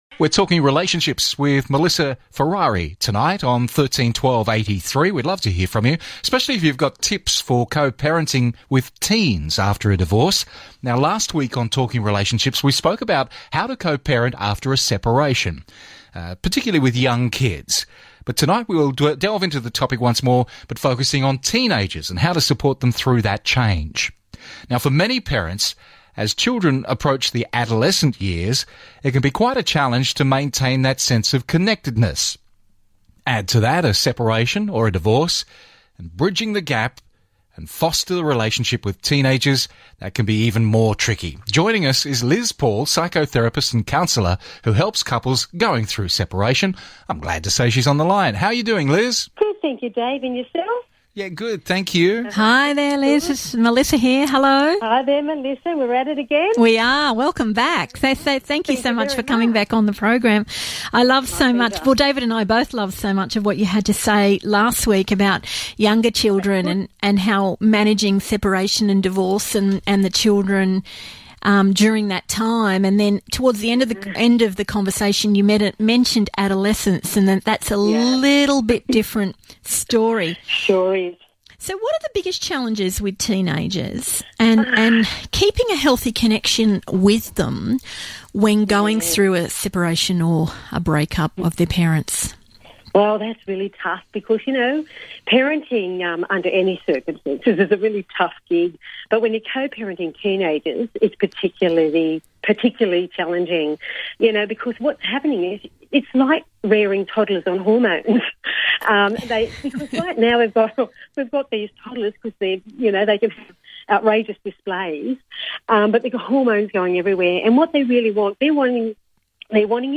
Radio interview: How parents can support adolescents during and after a divorce - Sydney Individuals and Couples Counselling, Relationship Counselling
Radio interview: How parents can support adolescents during and after a divorce